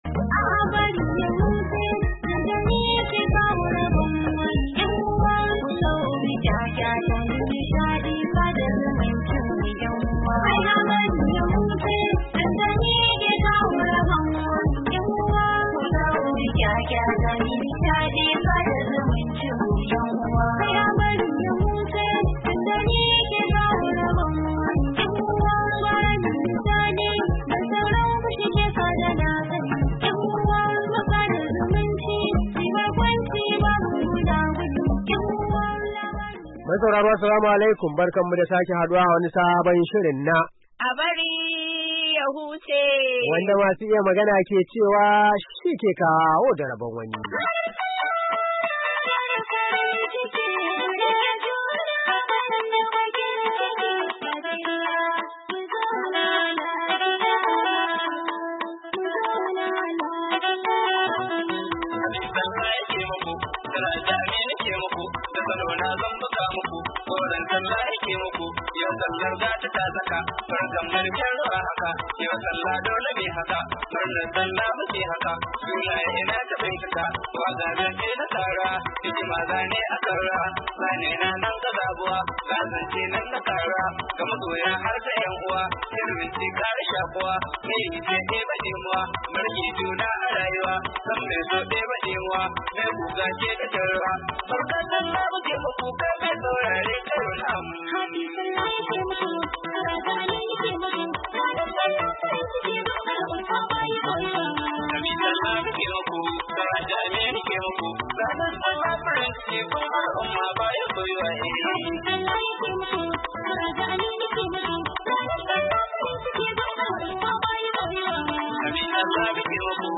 Saurari shirinmu na "A Bari Ya Huce" na ranar Asabar 15, Yuni 2018 domin jin labaran ban dariya na wannan makon da kuma irin kade kade da gaishe gaishe da suka sami shiga cikin shirin.